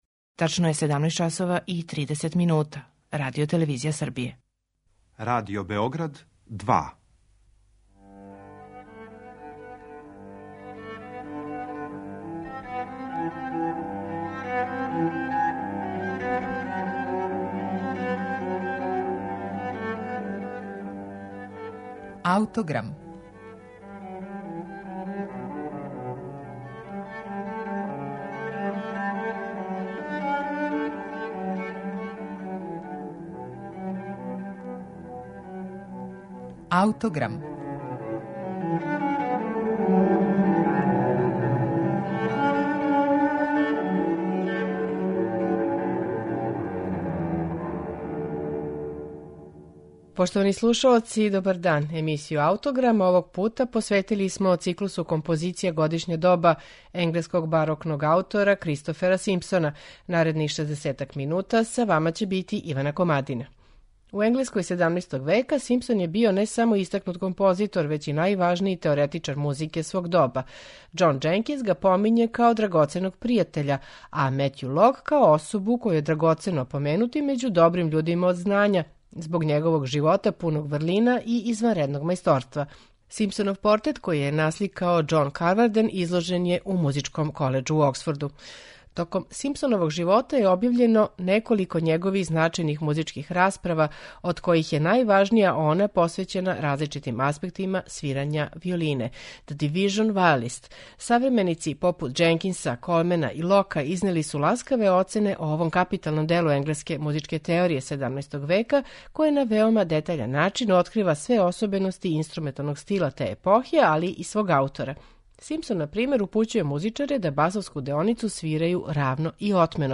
Циклус композиција за анасамбл виола "Годишња доба" Кристофера Симпсона није само врхунац опуса овог аутора већ и једно од последњих великих дела енглеског музичког стила XVI и XVII века. Аутор је овде програмски објединио четири троставачне свите, са идентичним распоредом ставова: фантазија, арија и гаљарда.
на оригиналним инструментима Симспоновог доба